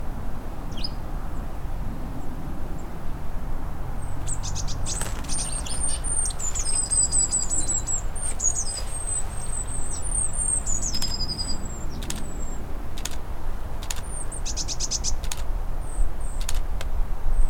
I had the mic open while doing my 30 min. backyard birding this morning.
On the recording one can hear Blue Tits suddenly warning loudly, and the Sparrowhawks wings hitting the tree at the 5 seconds mark.